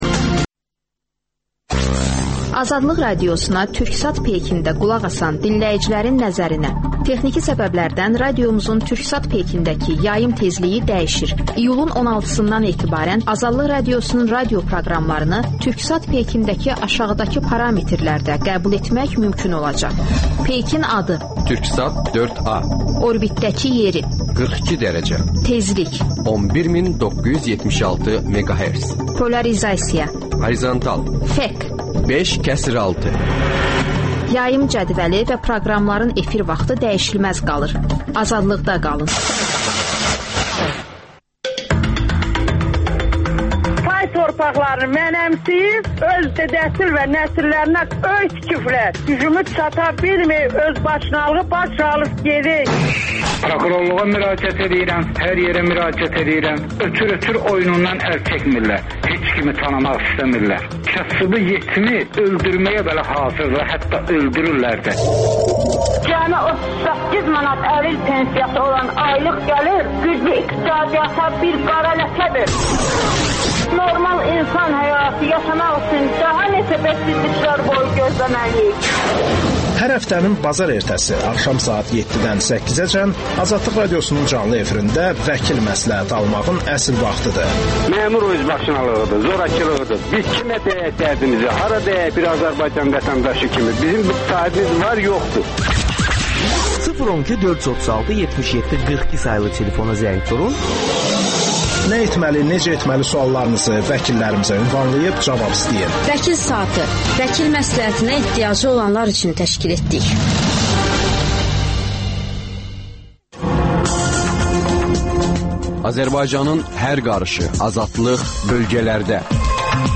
AzadlıqRadiosunun müxbirləri ölkə və dünyadakı bu və başqa olaylardan canlı efirdə söz açırlar. Günün sualı: Təyyarə qəzasına dünya necə reaksiya verməlidir?